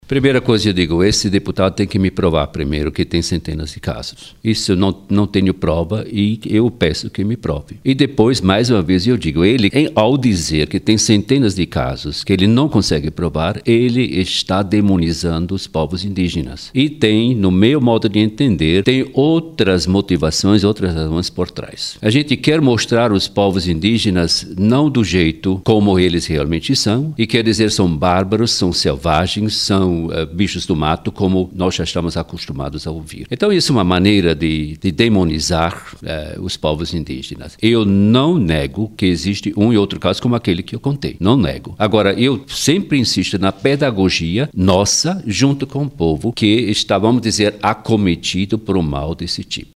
Bispo Erwin Kräutler sobre o deputado Henrique Afonso (PT-AM), autor de um projeto de lei para combater infantícios entre indígenas: "Ao dizer que há centenas de casos, que ele não consegue provar, ele está demonizando os povos indígenas."